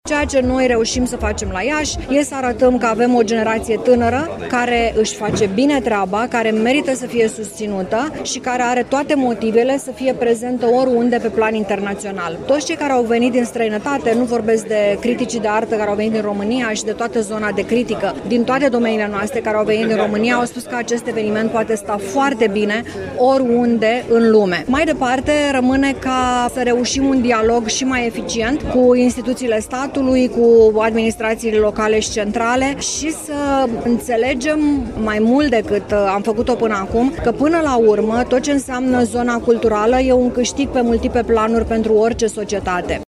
Organizatorul evenimentelor, Irina Schrotter